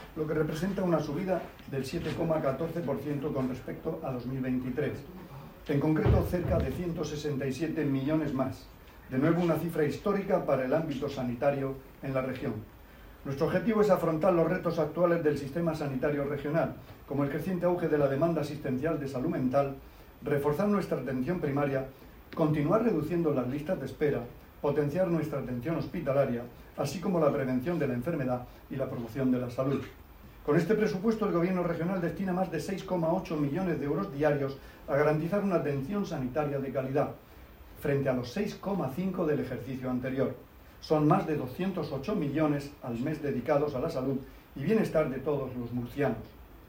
Declaraciones del Consejero de Salud